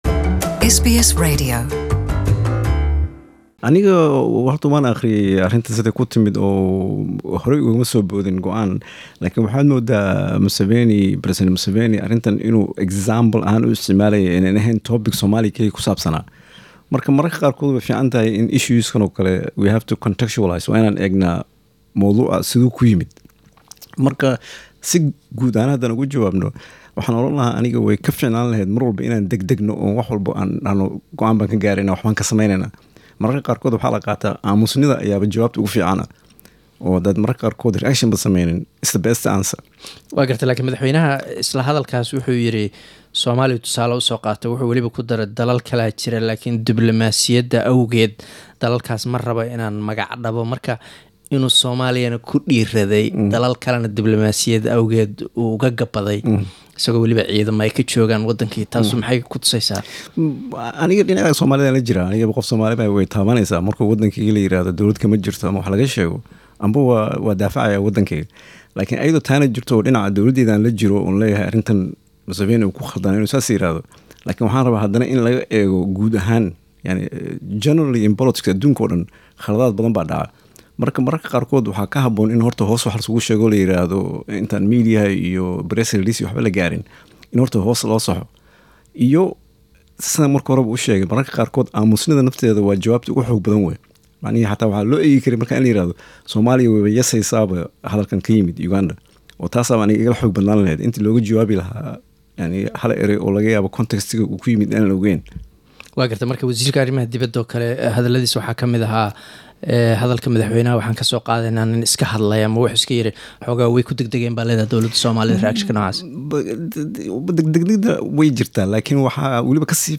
Interview with Former Somali federal minister, Mohamed Ibrahim
Waraysi: Wasiirkii hore ee isgaarsiinta Somalia, Mohamed Ibrahim